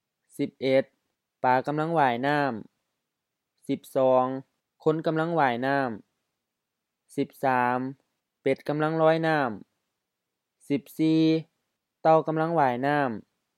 IsaanPronunciationTonesThaiEnglish/Notes
หว้ายน้ำ wa:i-na:m LF-HF ว่ายน้ำ to swim